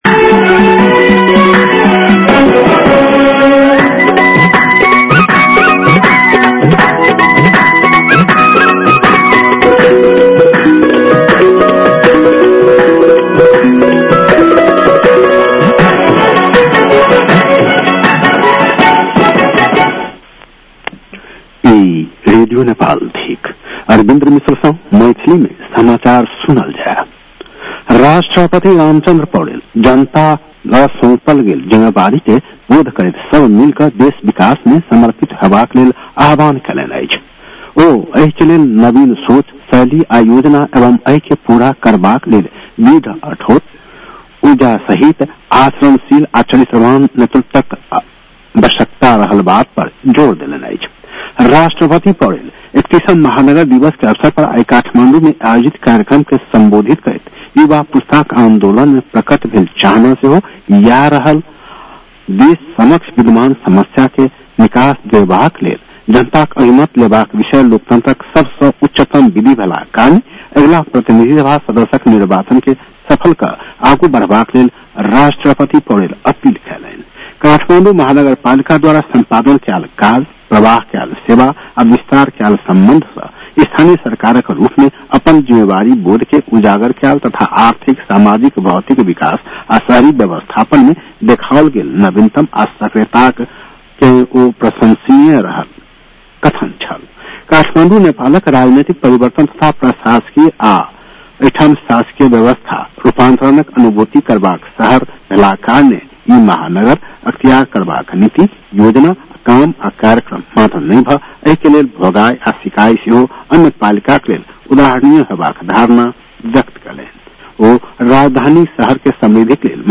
मैथिली भाषामा समाचार : २९ मंसिर , २०८२
6-pm-maithali-news-8-29.mp3